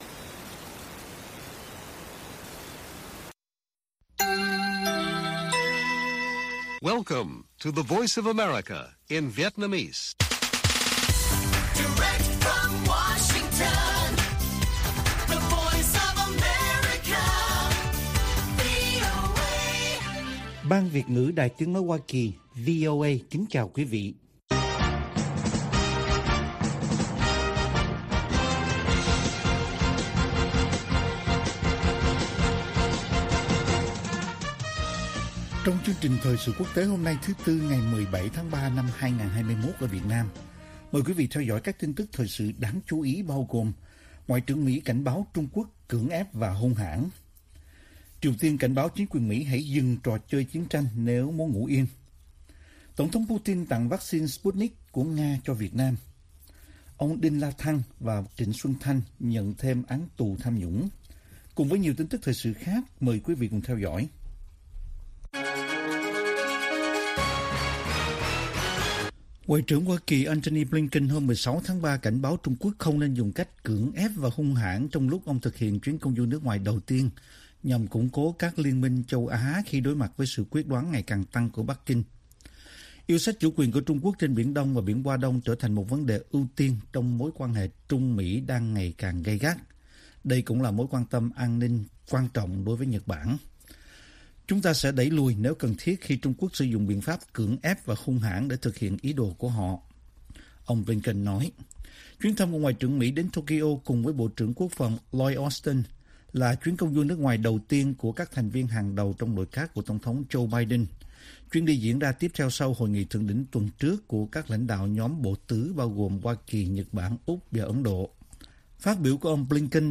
Bản tin VOA ngày 17/3/2021